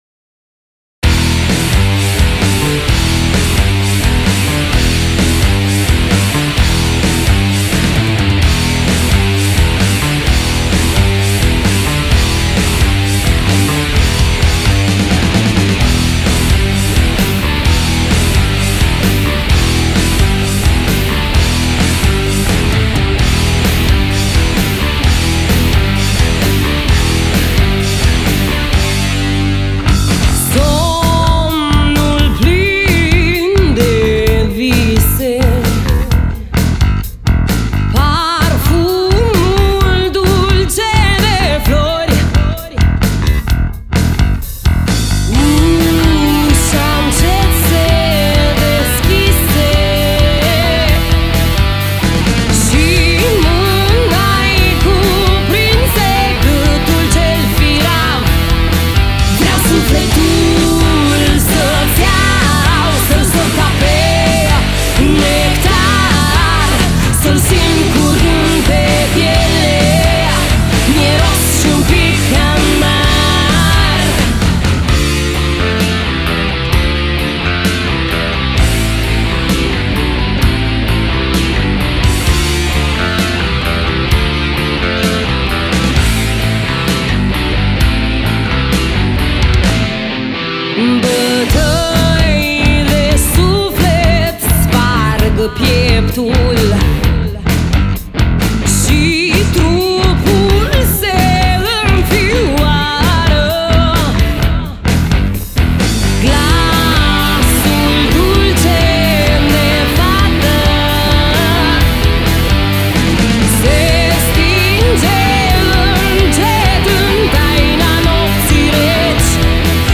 Gen muzical: Free Rock Alternative.